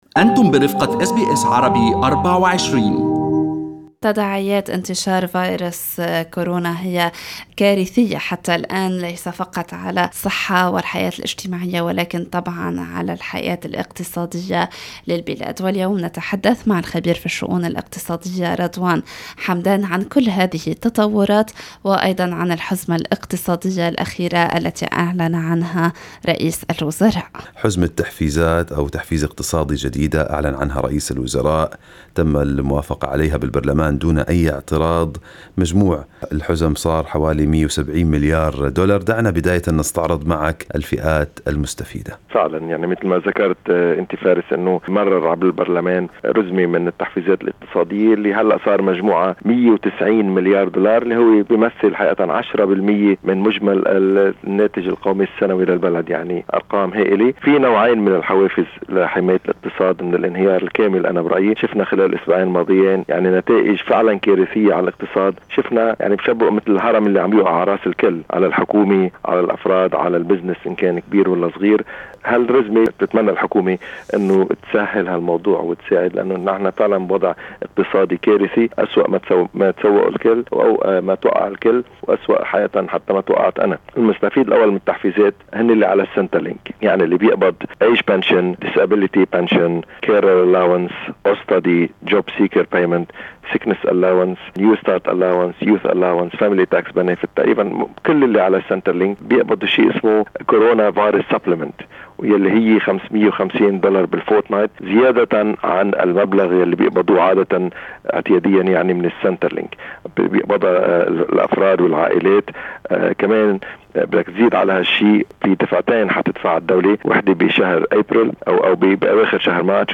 تعرفوا على أبرز المستفيدين من التحفيزات الاقتصادية من خلال الاستماع إلى اللقاء الكامل مع الخبير في الشؤون الاقتصادية